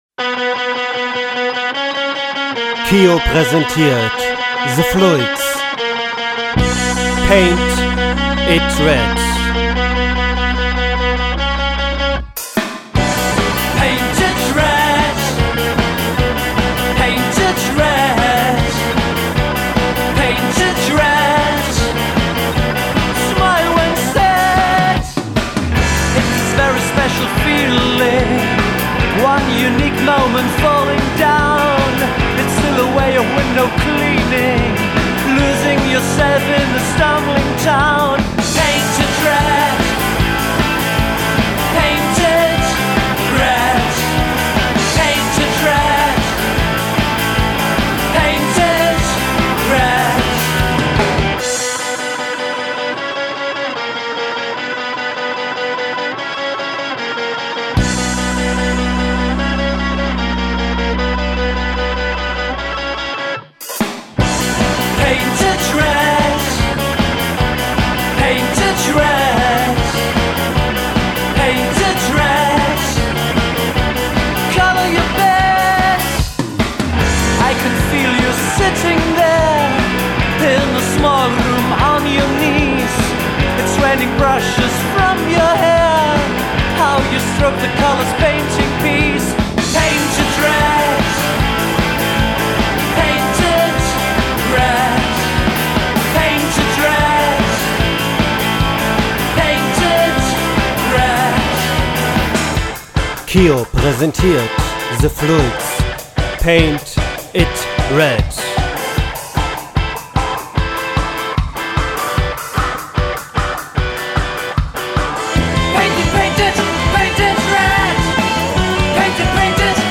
Exzentrischer und hysterischer Indie aus Deutschland.
Poetisch – Paranoid – Romantisch.